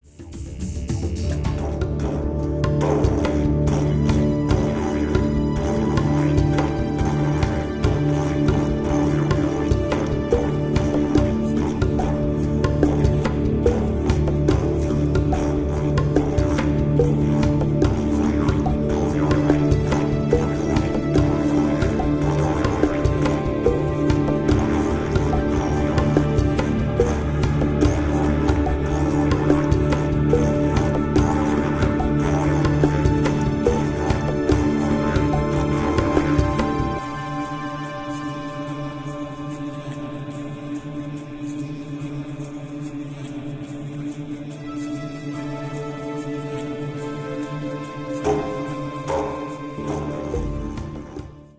tribal drum music
Electronix Ambient